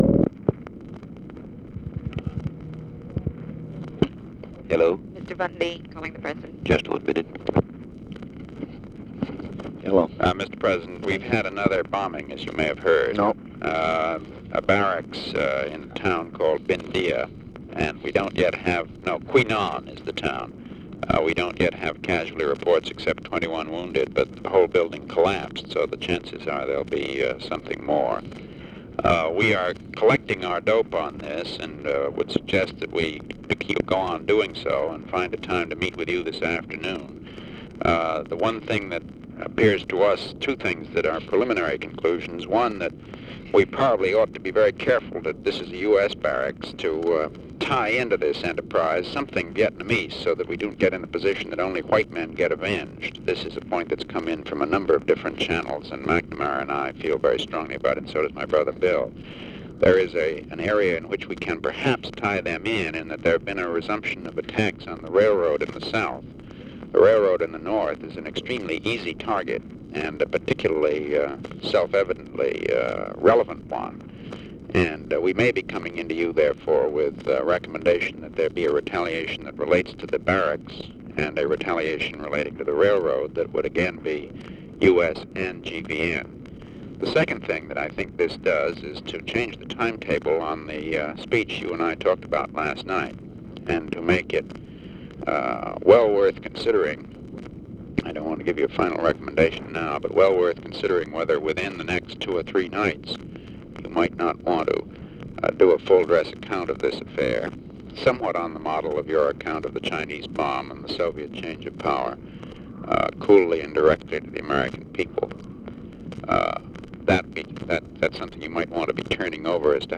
Conversation with MCGEORGE BUNDY and BILL MOYERS, February 10, 1965
Secret White House Tapes